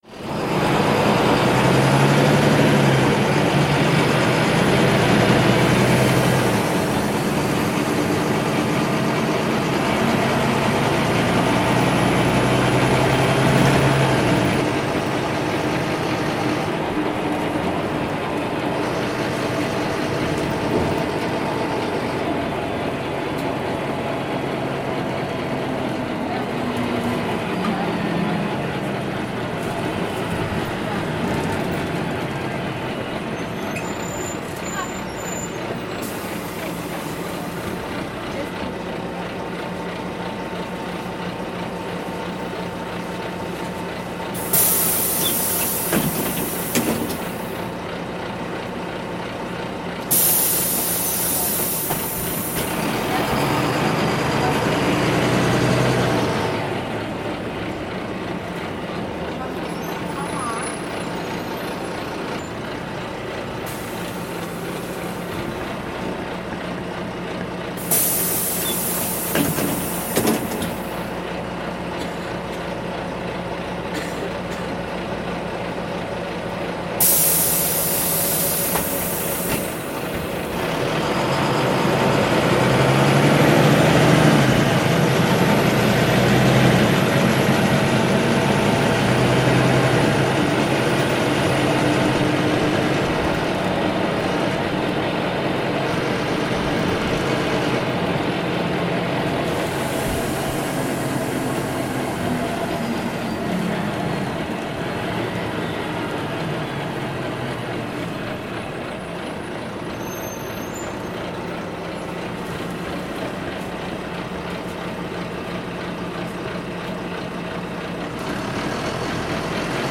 دانلود صدای اتوبوس واحد از ساعد نیوز با لینک مستقیم و کیفیت بالا
جلوه های صوتی
برچسب: دانلود آهنگ های افکت صوتی حمل و نقل